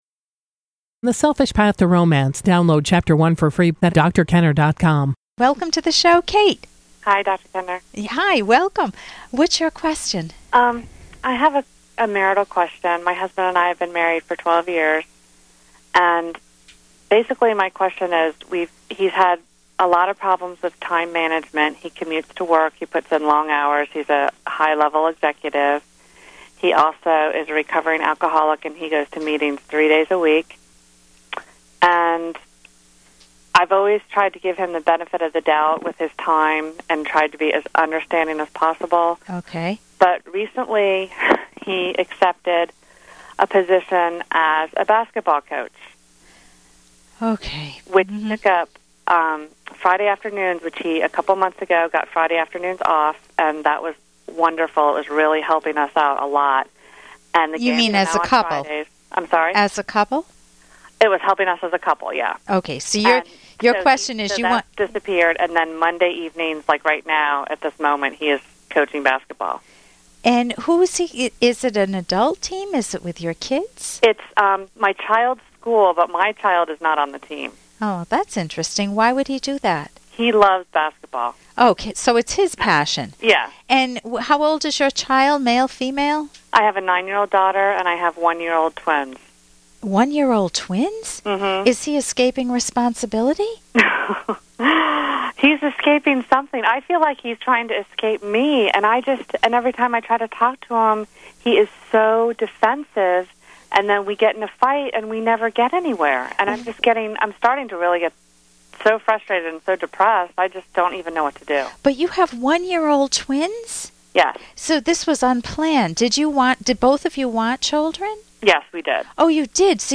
Episode from The Rational Basis of Happiness® radio show